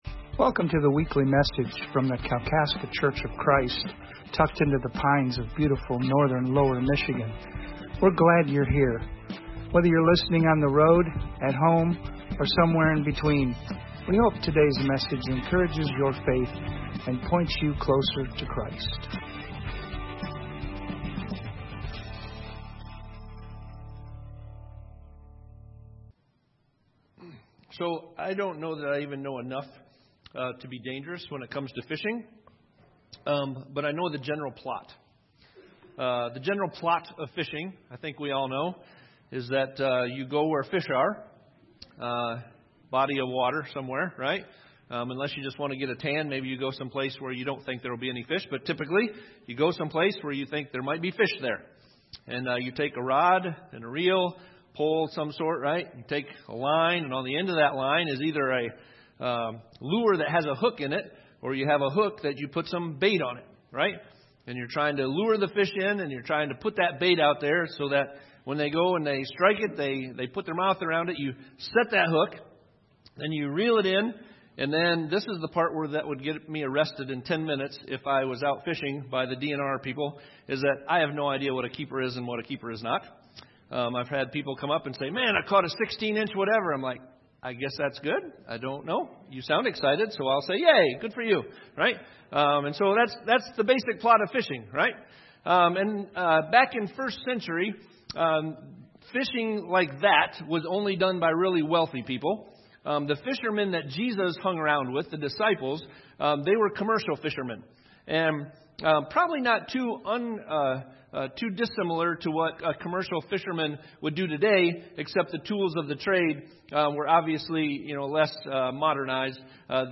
Sermon Series: Conversations with Jesus